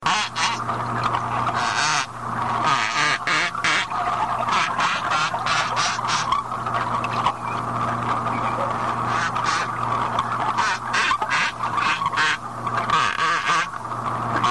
دانلود صدای دلفین 2 از ساعد نیوز با لینک مستقیم و کیفیت بالا
جلوه های صوتی